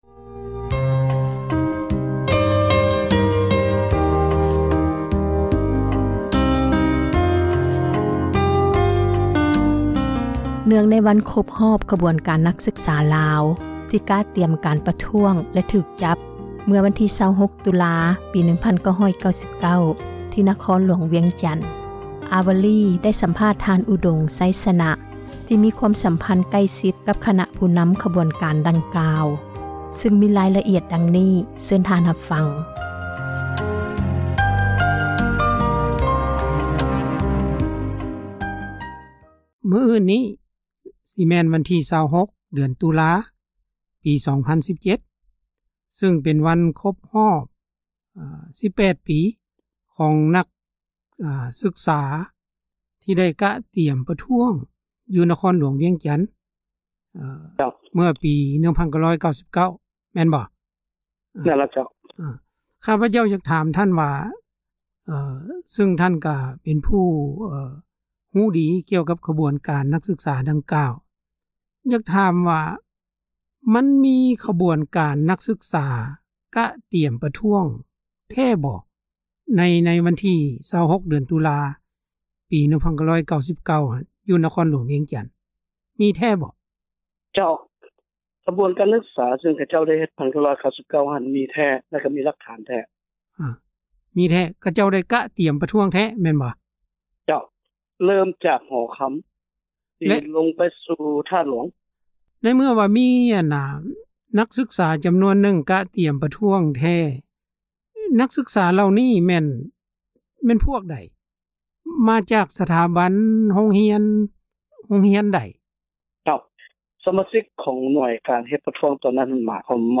ຂໍສັມພາດ